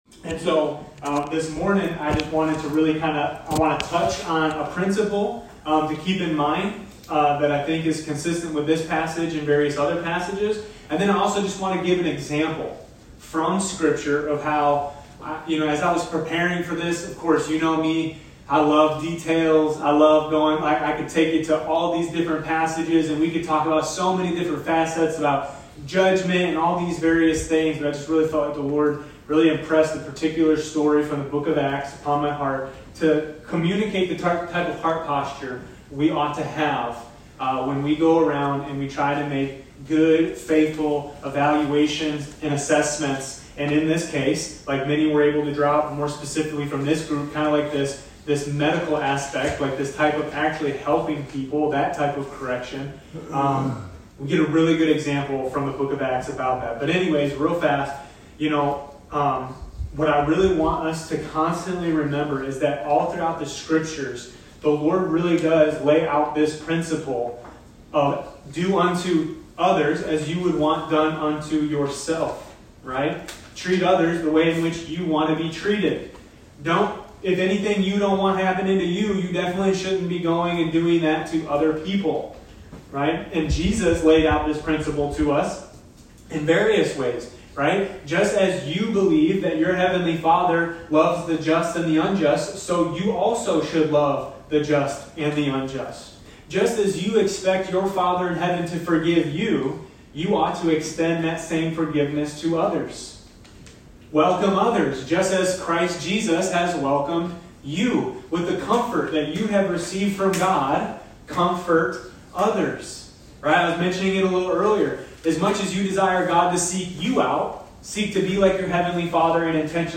Matthew 7:1-5 Service Type: Gathering We continue to study Jesus’ Sermon on the Mount.